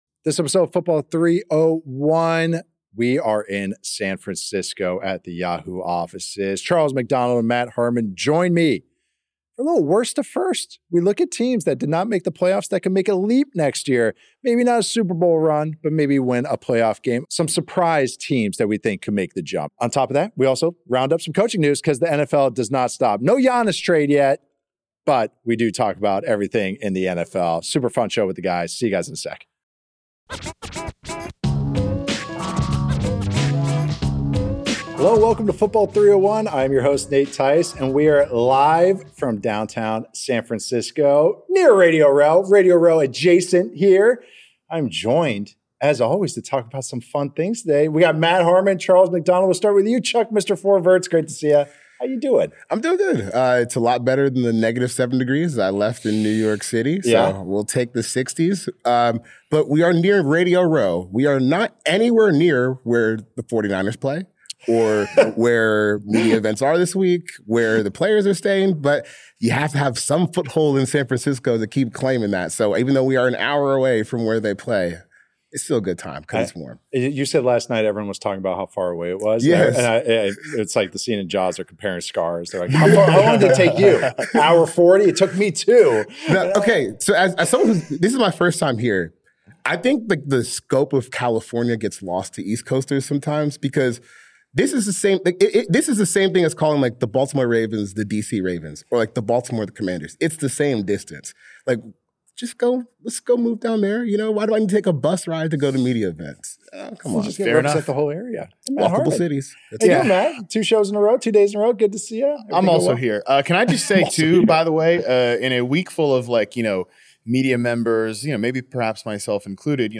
Next, the three hosts dive into their teams that could take a big leap after missing the playoffs this season.